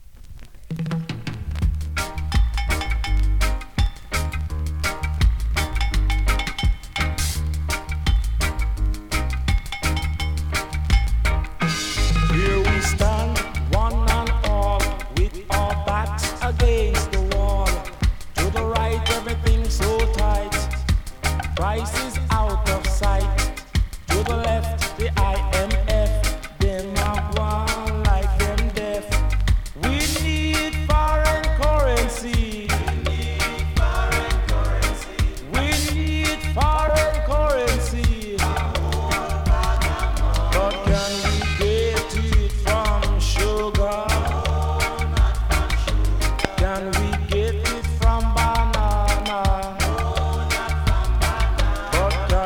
SKA〜REGGAE
スリキズ、ノイズかなり少なめの